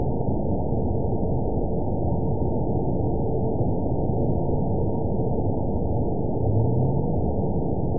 event 911398 date 02/25/22 time 02:01:34 GMT (3 years, 2 months ago) score 8.90 location TSS-AB02 detected by nrw target species NRW annotations +NRW Spectrogram: Frequency (kHz) vs. Time (s) audio not available .wav